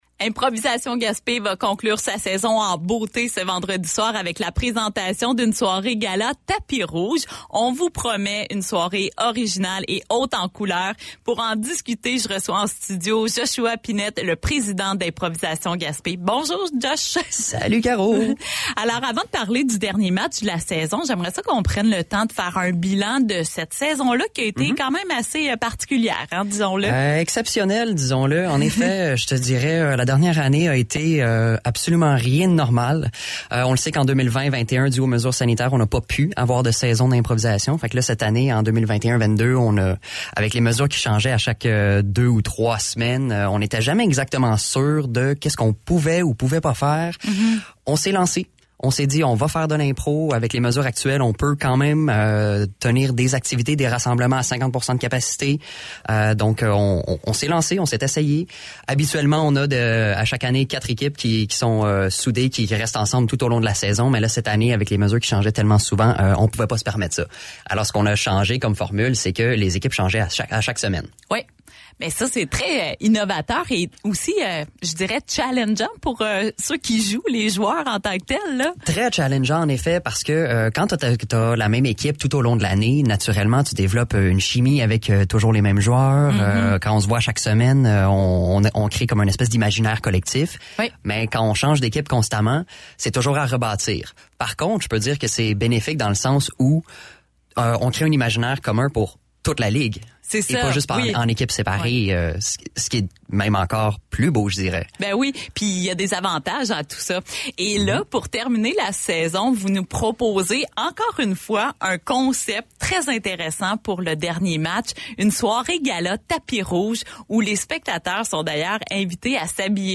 a reçu en studio